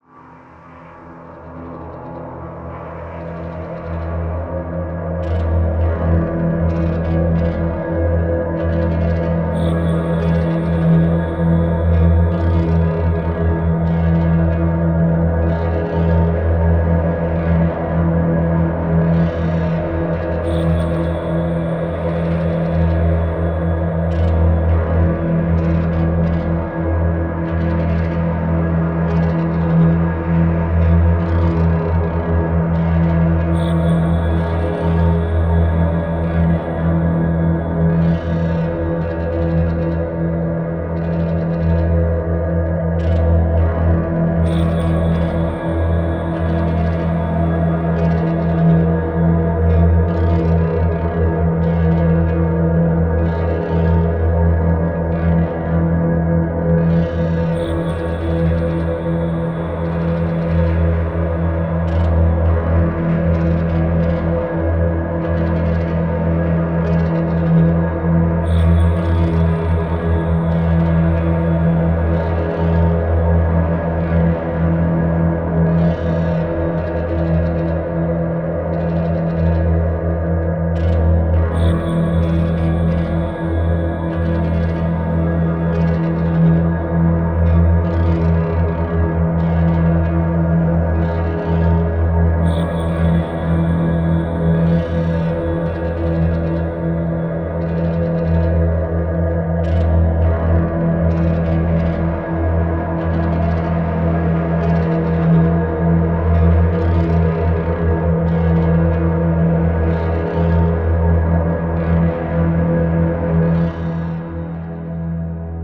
ha-distillery.wav